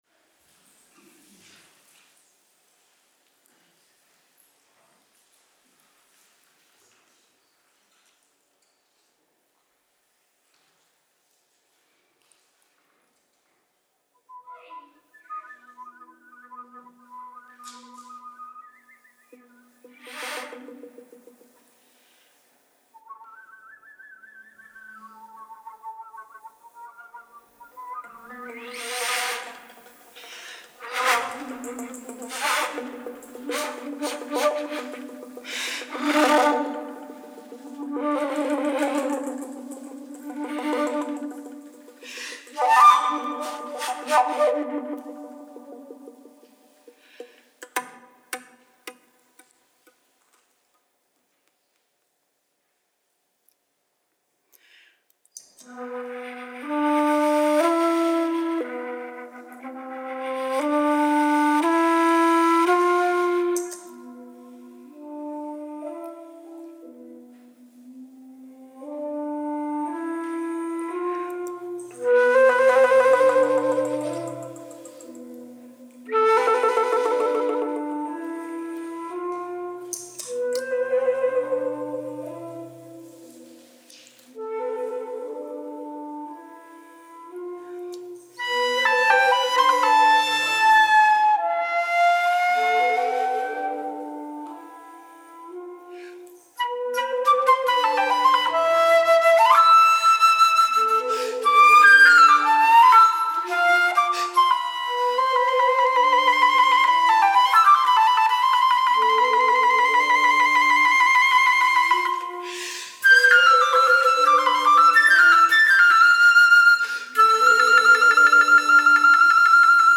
for flute/piccolo and Native American flute with loop pedal